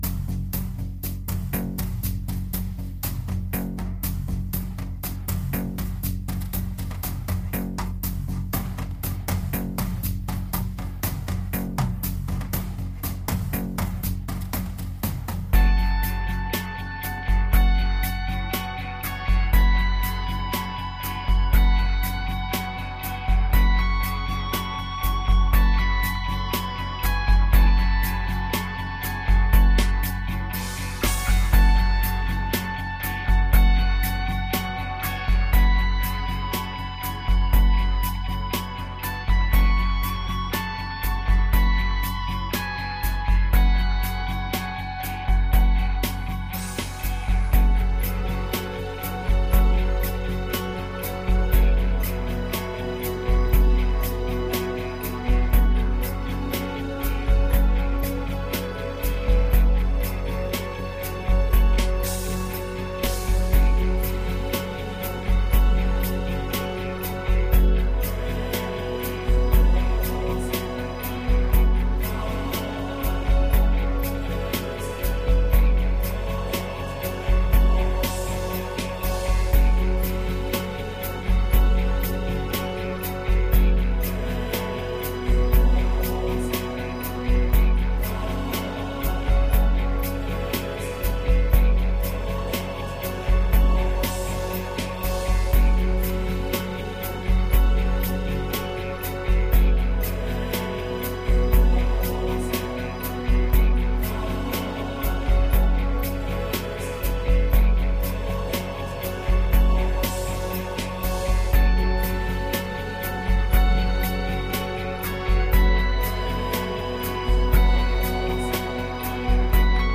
该唱片融入了诸多超凡脱俗的声音、并加入哥特式合唱团、吉他、鼓声等等形成了一个完整的多乐器音乐合集。